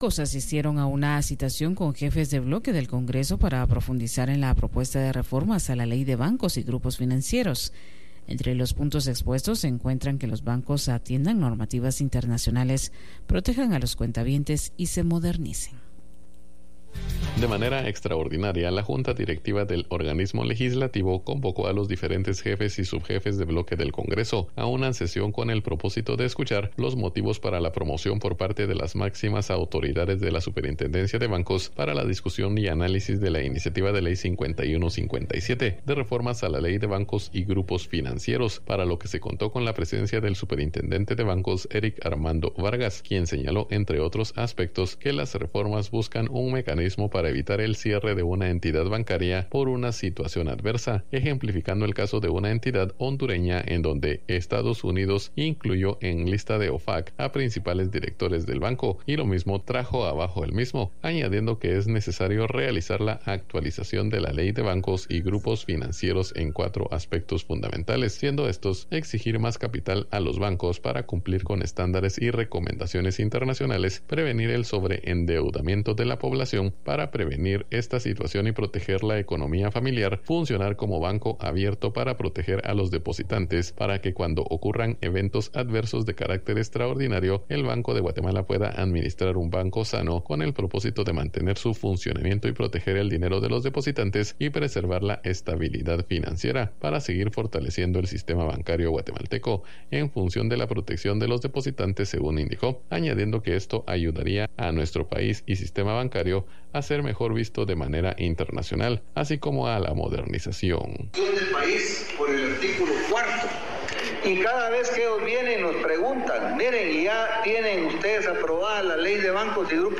Noticias Iniciativa de Ley 5157